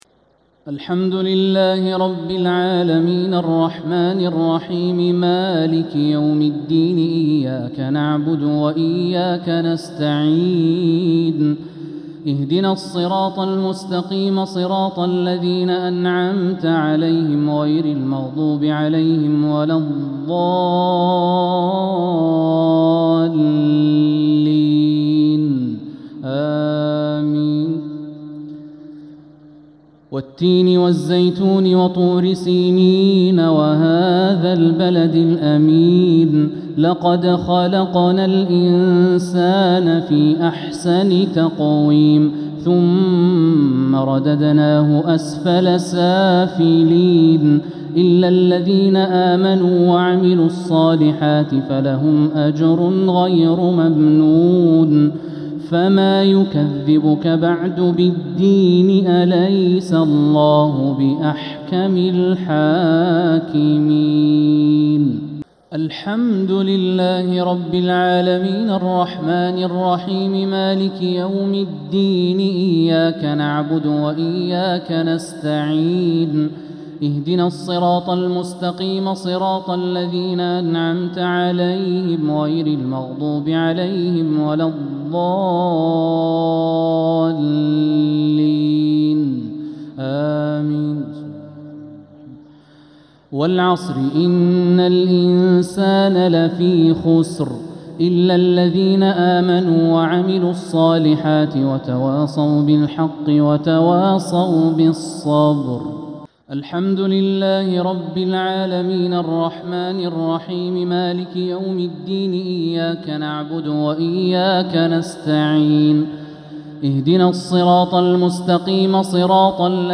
صلاة الشفع و الوتر ليلة 4 رمضان 1447هـ | Witr 4th night Ramadan 1447H > تراويح الحرم المكي عام 1447 🕋 > التراويح - تلاوات الحرمين